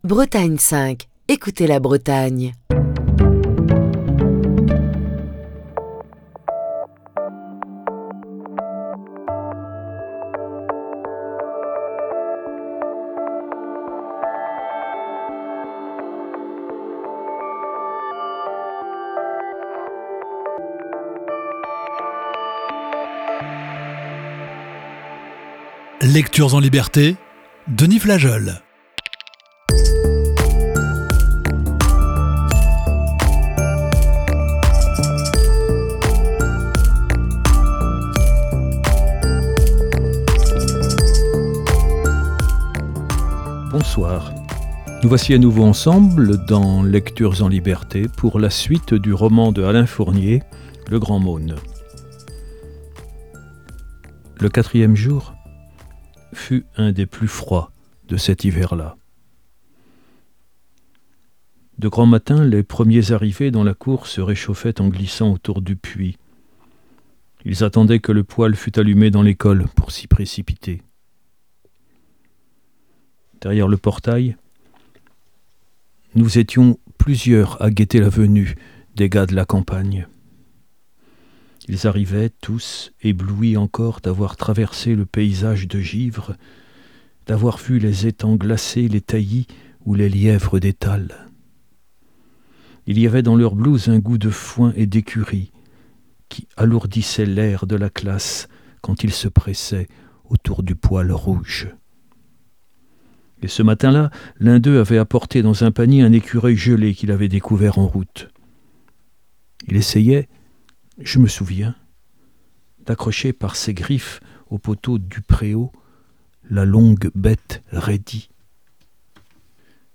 la lecture d'un classique de la littérature